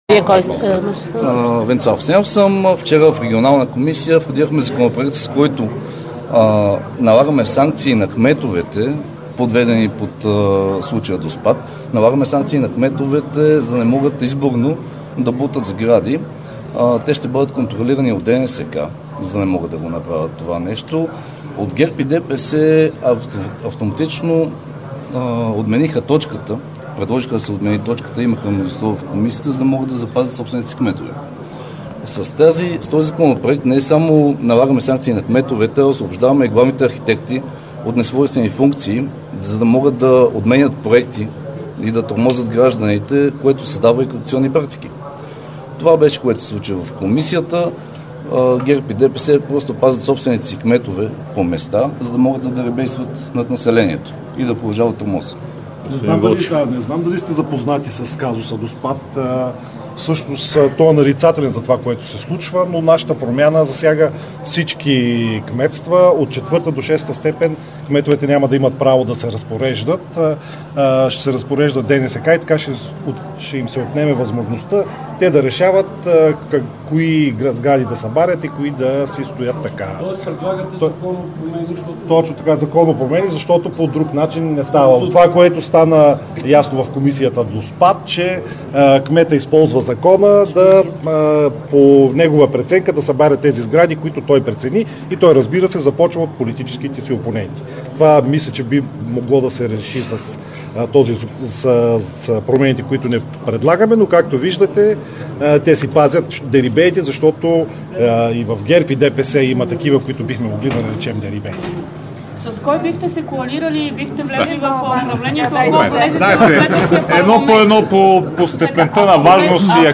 9.40 - Брифинг на председателя на ГЕРБ Бойко Борисов - директно от мястото на събитието (Народното събрание)